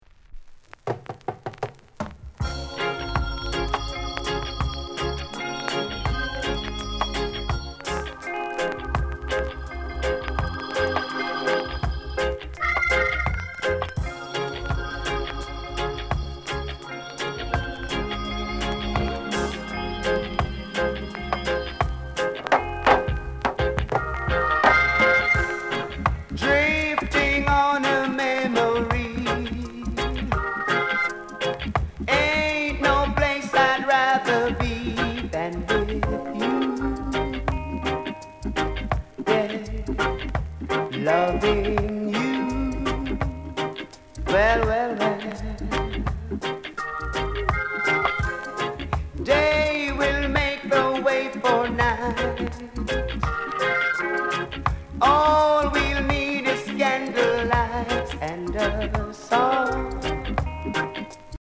Sound Condition VG(OK)
LOVERS ROCK